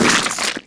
splat.wav